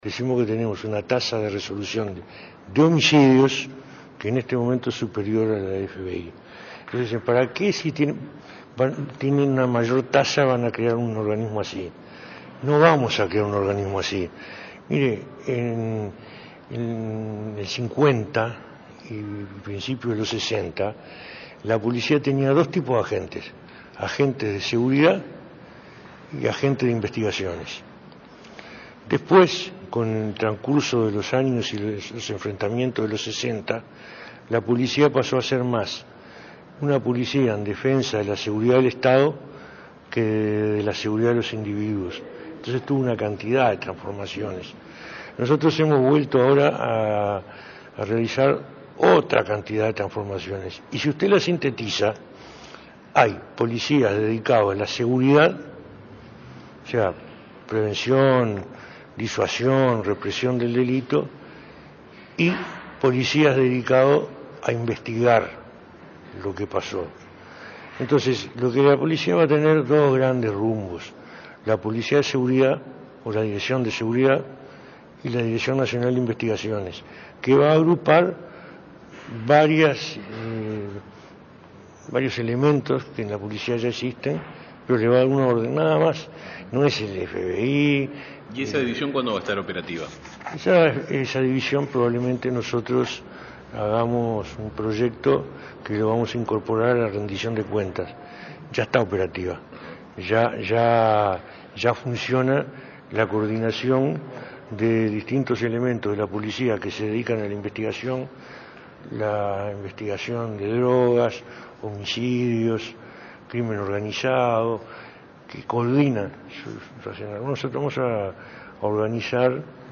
La Policía tendrá dos grandes rumbos: la Dirección de Seguridad y la nueva Dirección Nacional de Investigaciones, que agrupará elementos existentes y le dará un orden, señaló el ministro del Interior, Eduardo Bonomi, a la prensa.